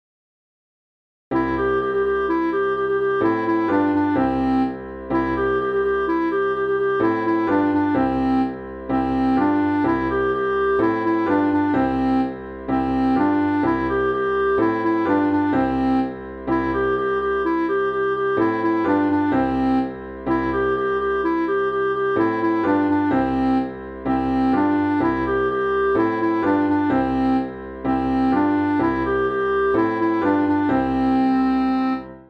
The Hello Song (Cançó tradicional escocesa)
Interpretació musical de la cançó tradicional escocesa